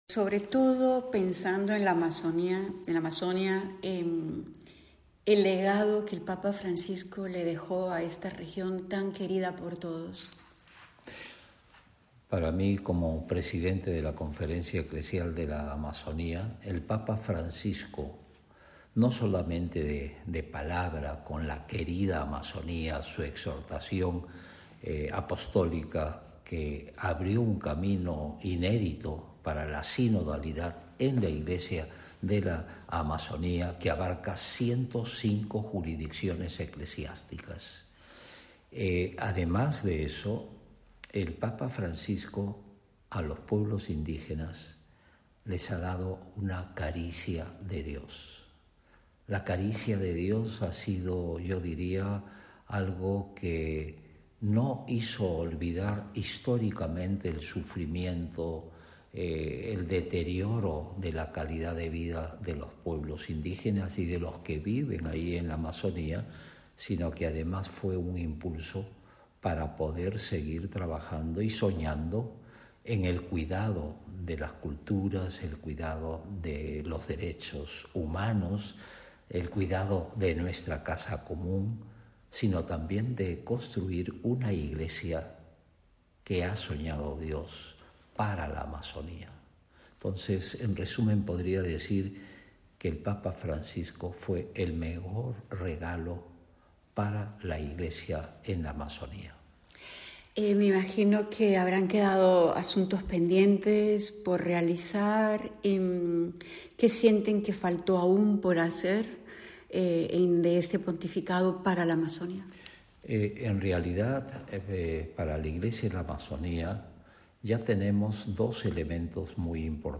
Complete interview with Cardinal Pedro Parito
An interview with Cardinal Pedro Barretto, head of the ecclesiastical conference in Amazon, Cama.